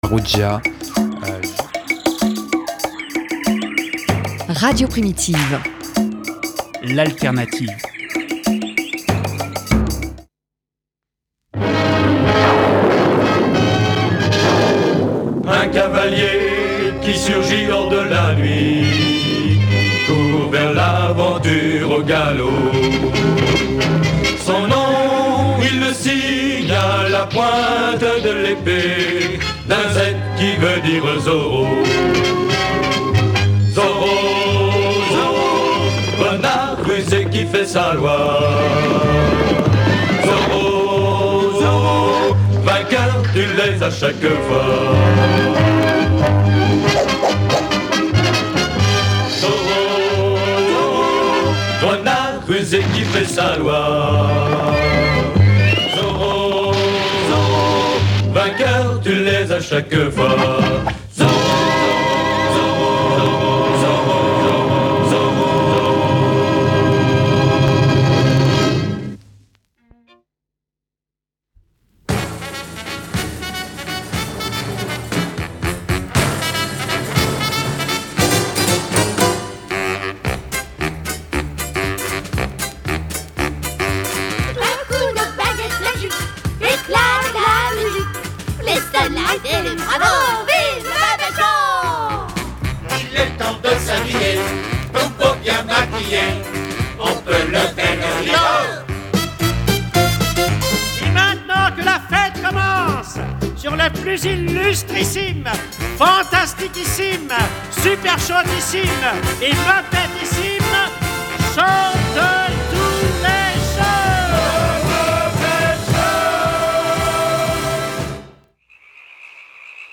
indic Z + extr. Muppet Show + sons grenouilles
bruits crapauds